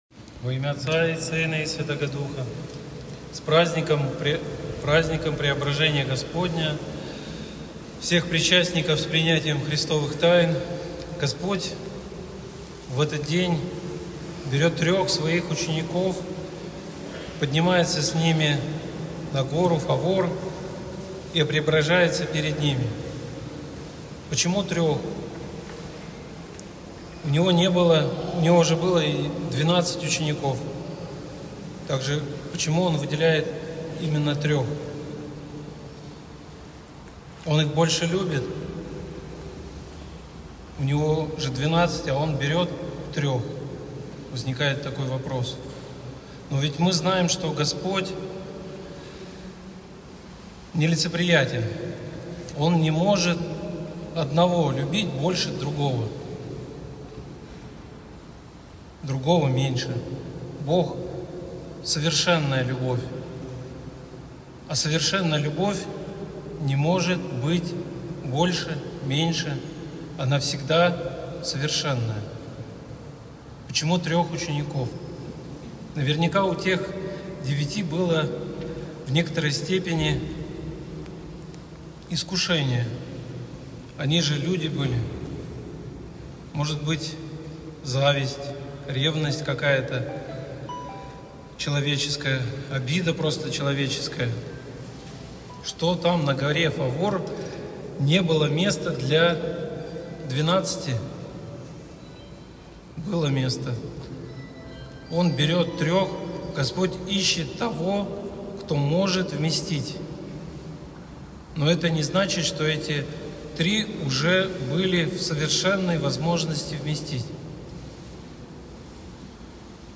Преображение-Господне.mp3